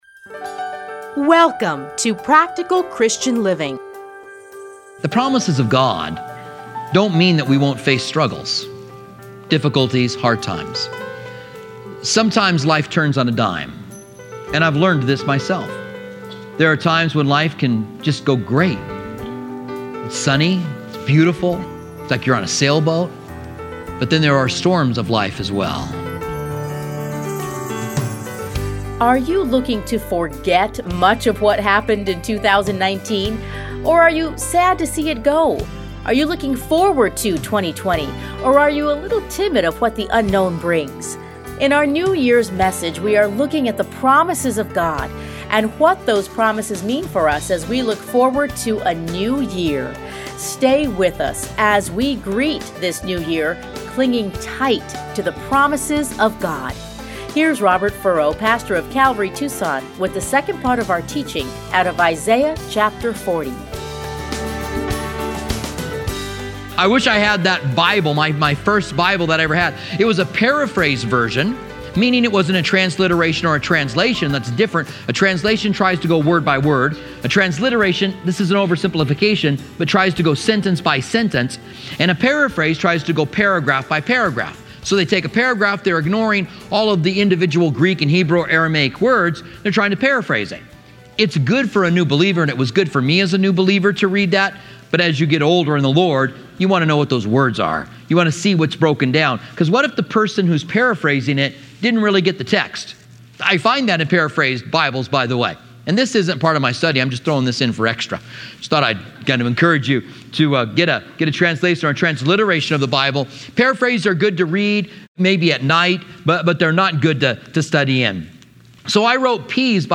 Listen here to his 2018 New Year's Eve message.